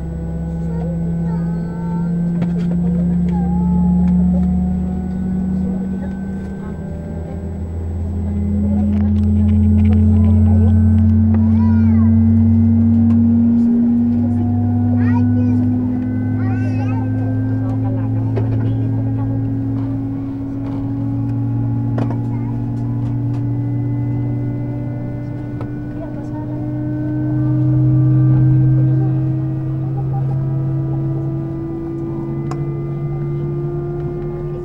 A320-family/Sounds/SASA/V2500/start/v2500-start2.wav at 4fb28daa99c7c1ab31d363d0beabe9e5e7c58f38
v2500-start2.wav